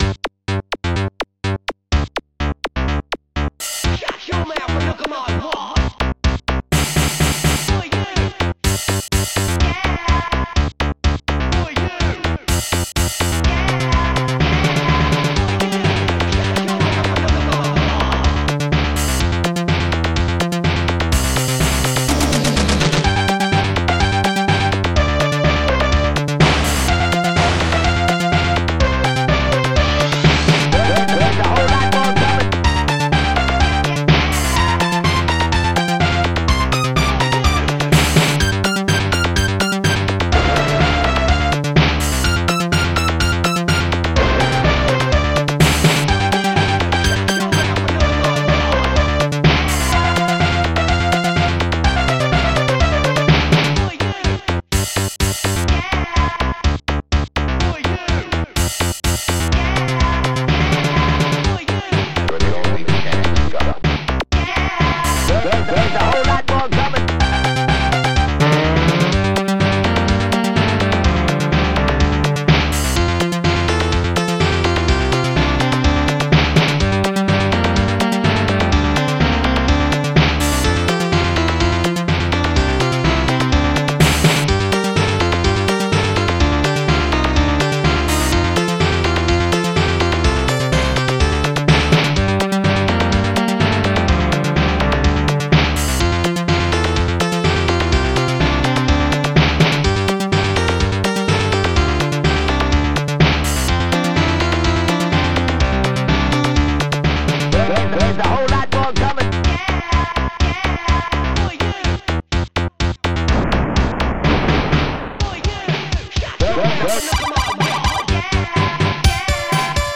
st-05:bassguitar14
st-05:bloodbrass
st-05:octavestrings
st-05:openhihat
st-02:snare9
st-05:bassdrum12